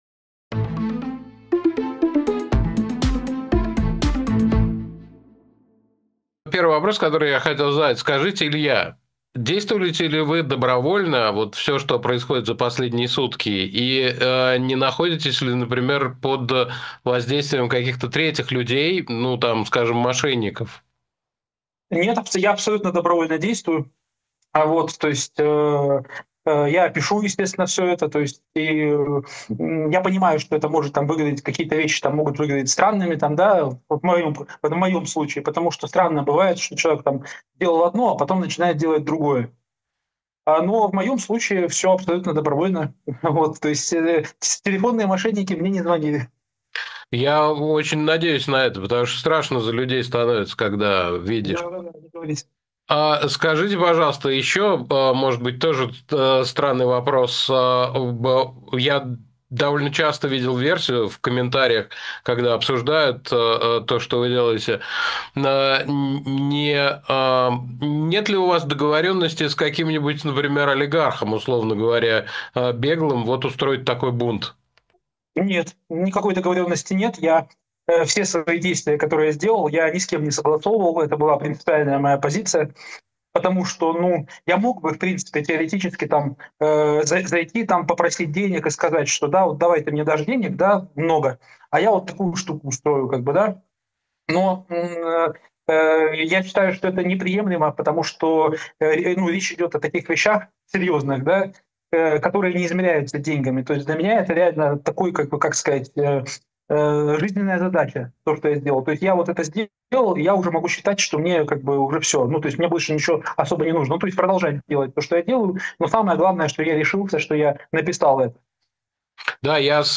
Илья Ремесло о своём бунте, конце власти Путина и почему он «мочил» ФБК Интервью на Breakfast Show